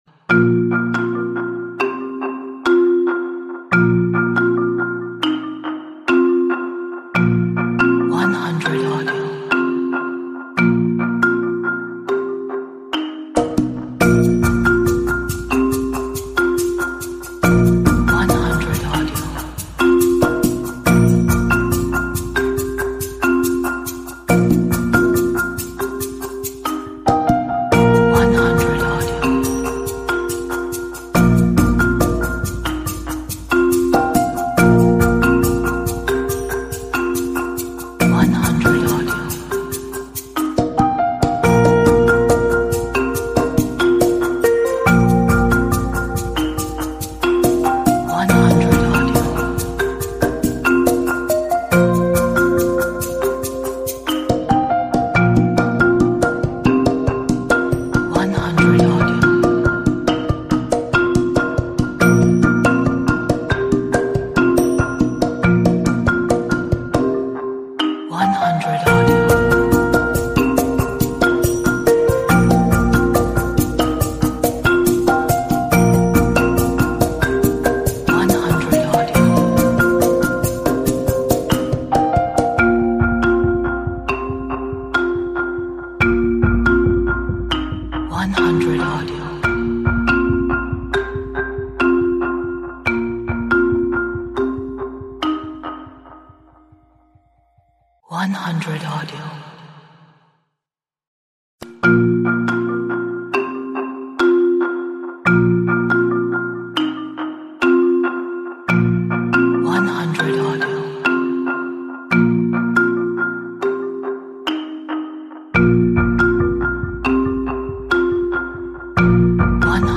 An inquisitive & curious cinematic inspired track